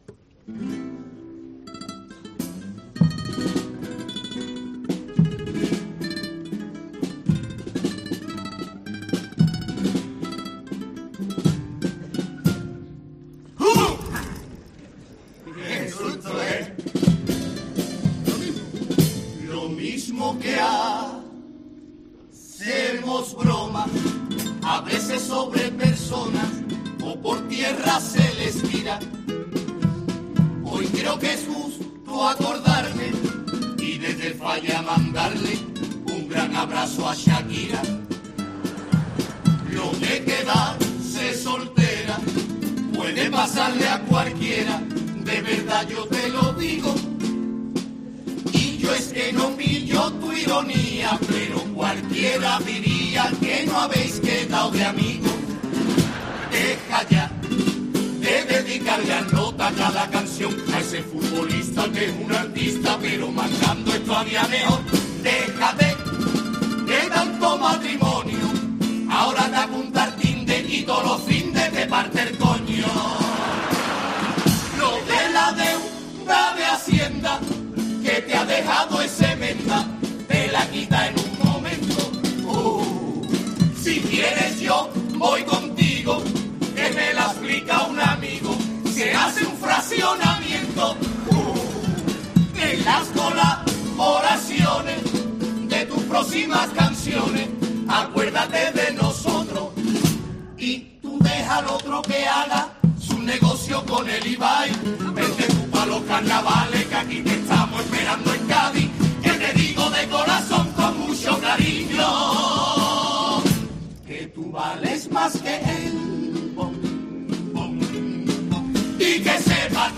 Es por todo ello por lo que la ruptura entre Shakira y Gerard Piqué tenía que estar presente en el escenario del Gran Teatro Falla, lugar en el que se celebra el Concurso de Agrupaciones del Carnaval de Cádiz.
Un pasodoble que levantó al público del Gran Teatro Falla y que generó un gran impacto por su originalidad y humor de principio a fin.